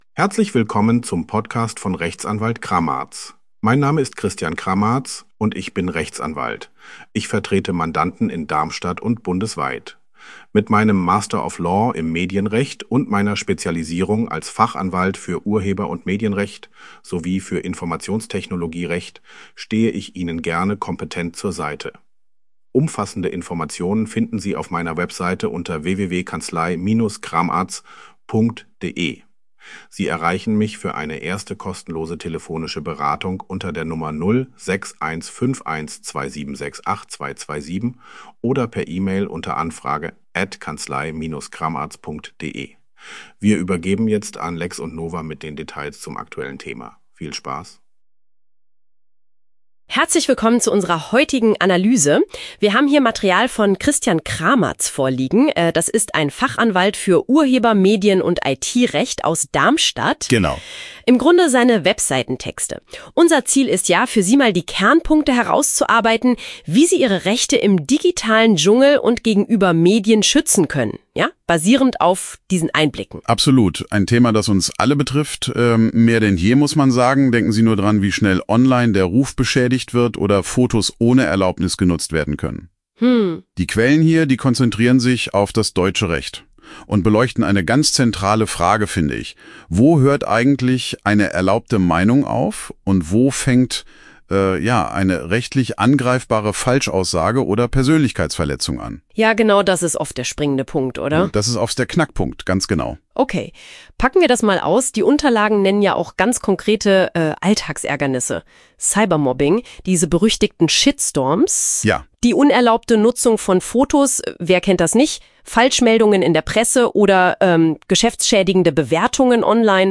In dieser aufschlussreichen Podcast-Episode beleuchten Ihre Moderatoren die komplexen Aspekte des Medienrechts.